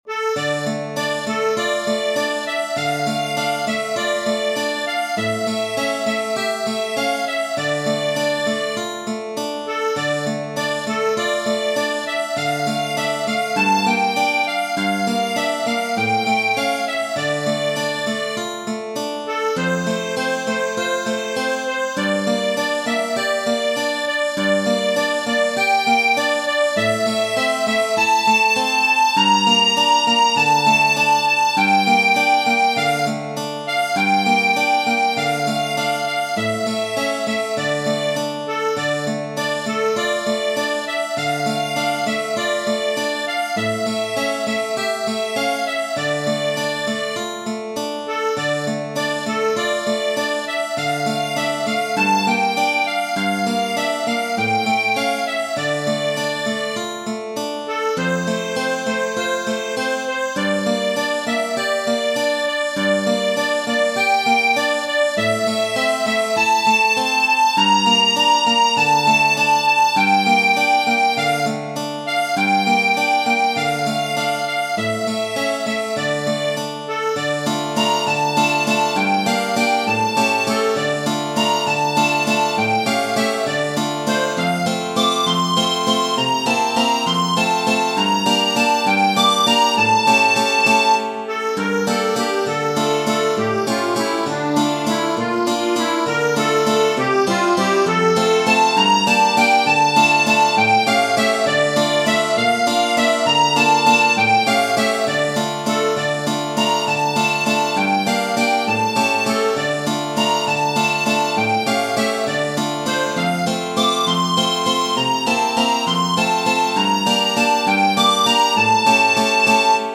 Tradizionale Genere: Folk "Jakobsleiter", (traduzione in italiano: la scala di Giacobbe), è un brano di origine israeliana utilizzato come una danza in cerchio.